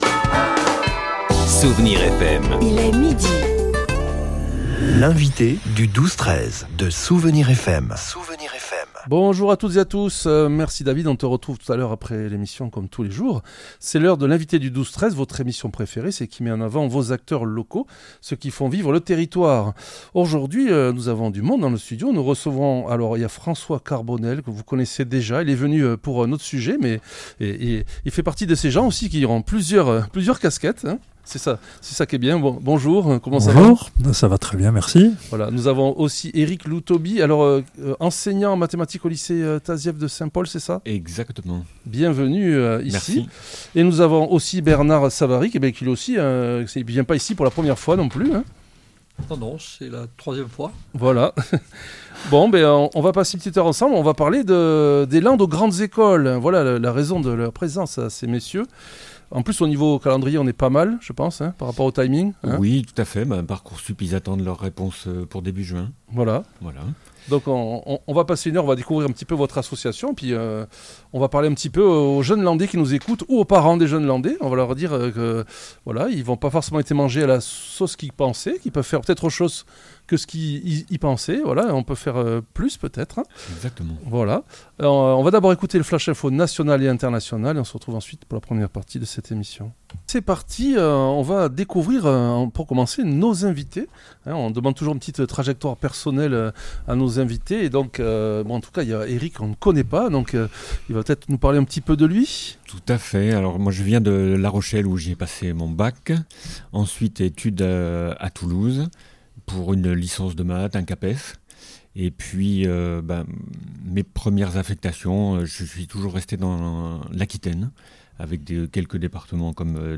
L'entretien a mis en lumière l'importance des rôles modèles, notamment à travers l'exposition "Technologie, nom féminin".